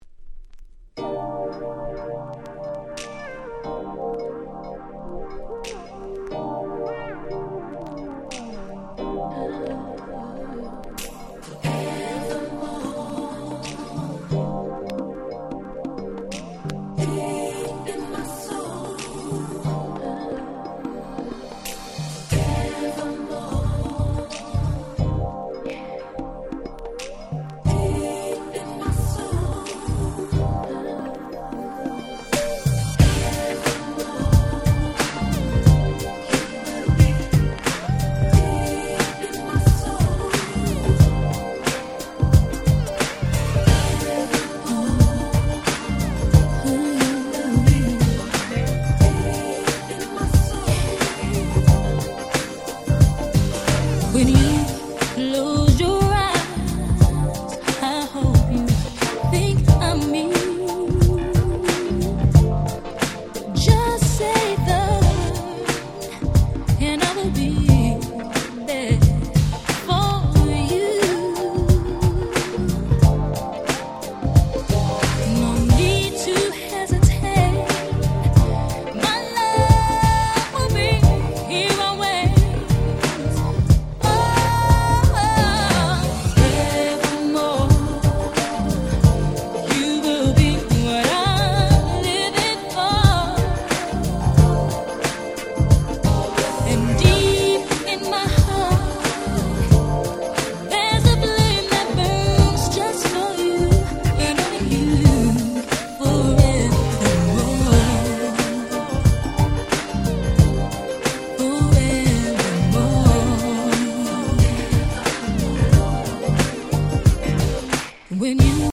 96' Smash Hit R&B !!